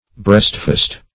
Search Result for " breastfast" : The Collaborative International Dictionary of English v.0.48: Breastfast \Breast"fast`\, n. (Naut.) A large rope to fasten the midship part of a ship to a wharf, or to another vessel.